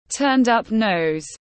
Mũi hếch tiếng anh gọi là turned-up nose, phiên âm tiếng anh đọc là /tɜːn nəʊz/ .
Turned-up nose /tɜːn nəʊz/